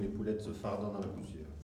Elle provient de Saint-Gervais.
Langue Maraîchin
Catégorie Locution ( parler, expression, langue,... )